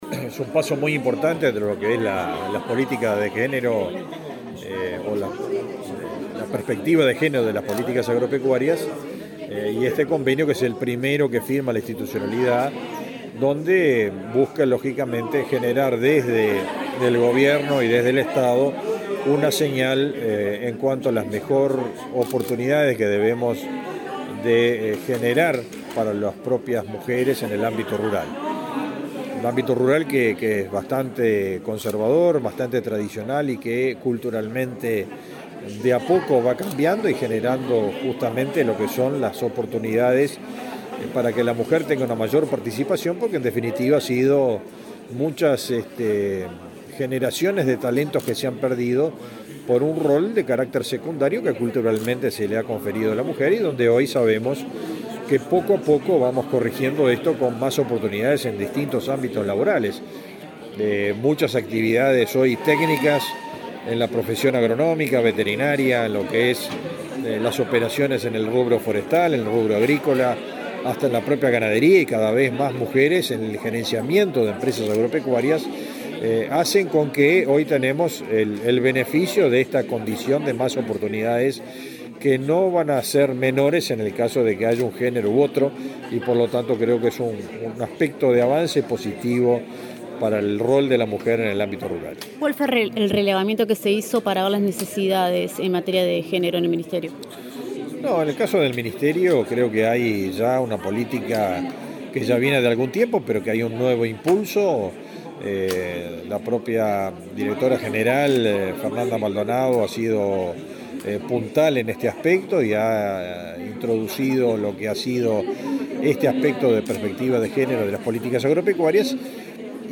Declaraciones a la prensa del ministro de Ganadería, Fernando Mattos
Declaraciones a la prensa del ministro de Ganadería, Fernando Mattos 04/07/2022 Compartir Facebook X Copiar enlace WhatsApp LinkedIn El Instituto Nacional de las Mujeres (Inmujeres) y el Instituto Nacional de Investigación Agropecuaria (INIA) firmaron un convenio para implementar un modelo de calidad con equidad de género. El ministro de Ganadería, Fernando Mattos, dialogó con la prensa sobre la importancia de la temática.